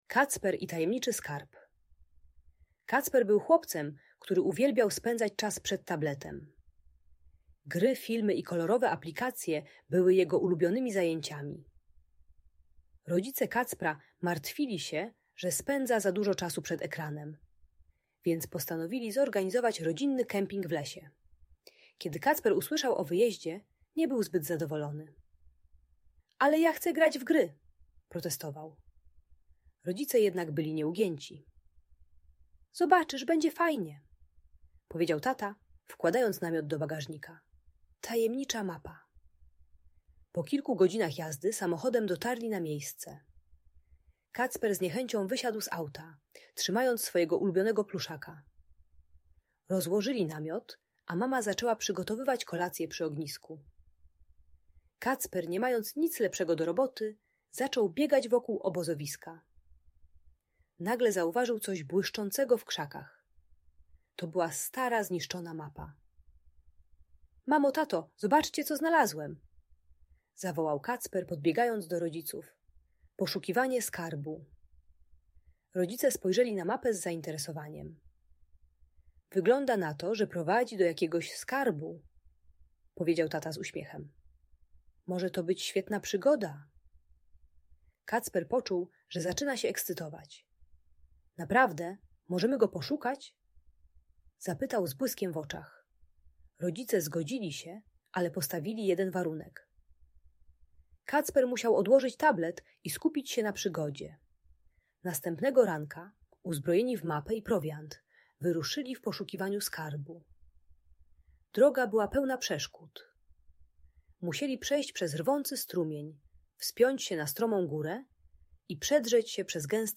Kacper i Tajemniczy Skarb - Audiobajka dla dzieci